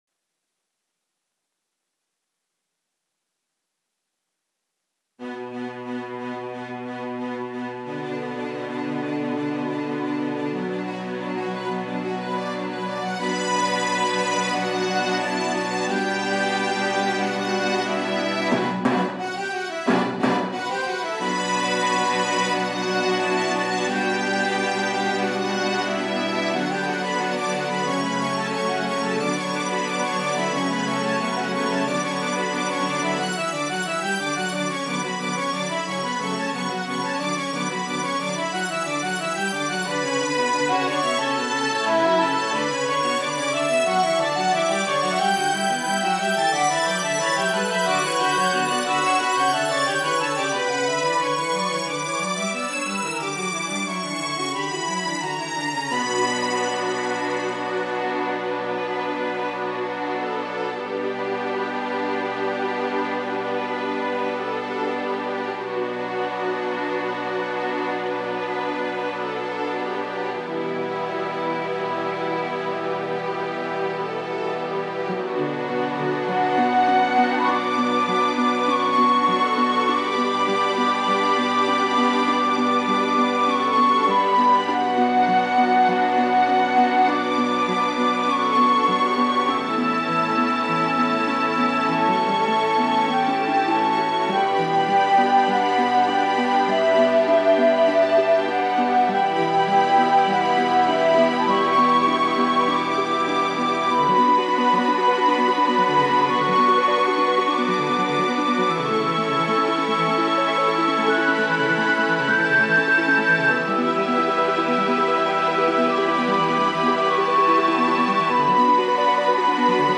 nk-OrchestraleFantomSRX06.mp3